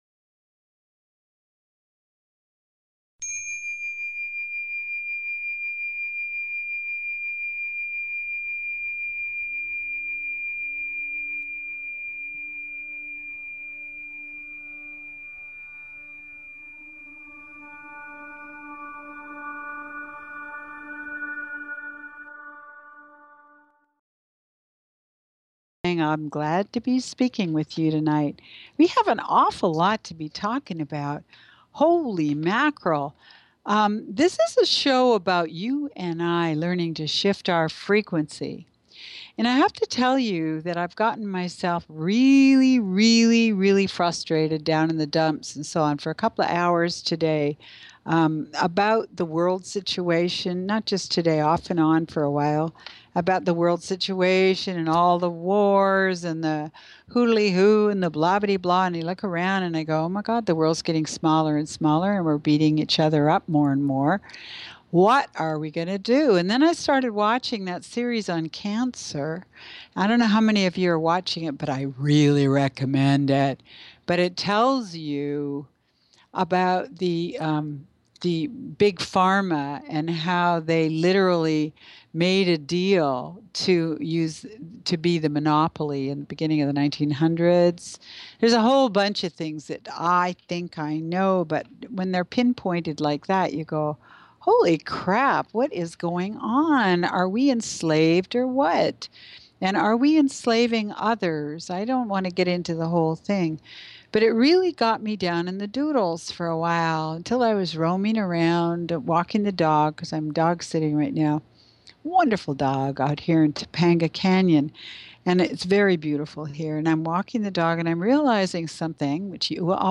Radiance By Design is specifically tailored to the energies of each week and your calls dictate our on air discussions. Together we explore multi-dimensional realities, healing through energy structures, chakra tuning, the complexities